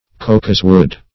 cocoswood \co"cos*wood`\